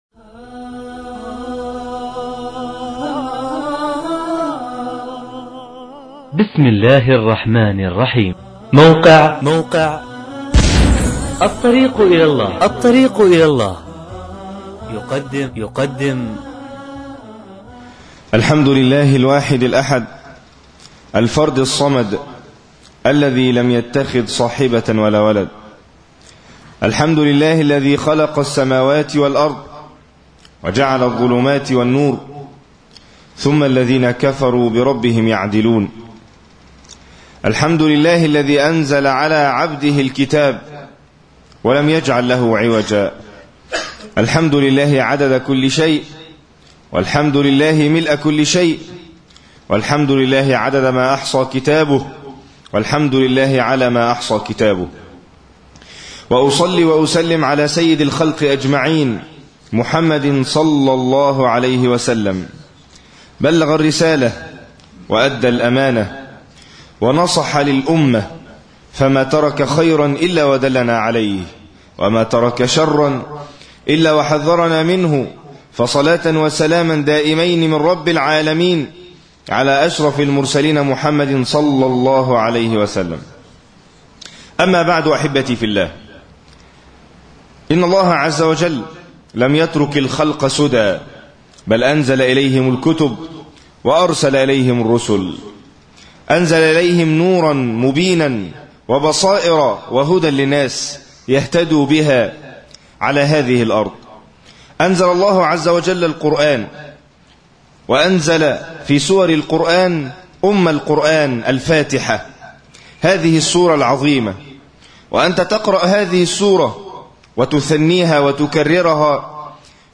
خطبة ( دُعاة على أبواب جهنم